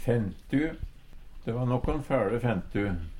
Høyr på uttala Ordklasse: Substantiv hokjønn Attende til søk